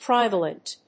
音節tri・va・lent 発音記号・読み方
/trὰɪvéɪlənt(米国英語)/